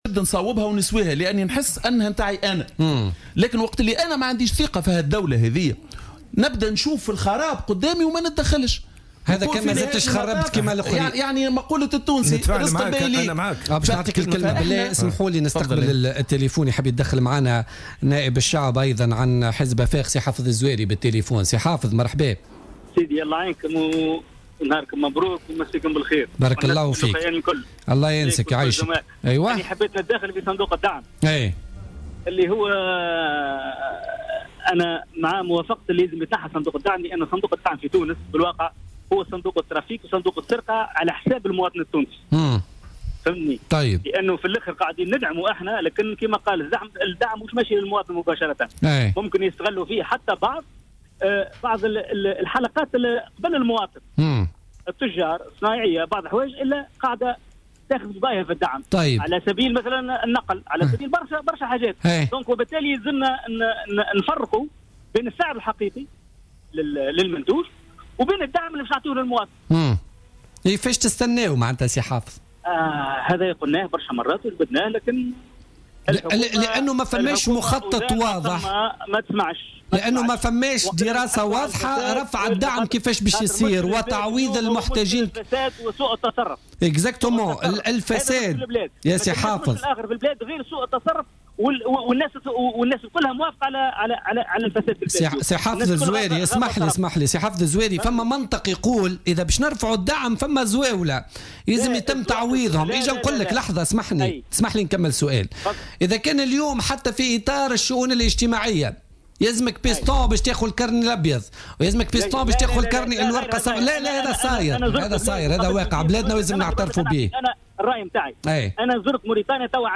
قال حافظ الزواري النائب عن حزب افاق تونس في مداخلة له في بوليتيكا اليوم الإثنين 23 ماي 2016 إنه يؤيد إلغاء صندوق الدعم في تونس معتبرا أنه صندوق "الترافيك" والسرقة على حساب المواطن على حد قوله.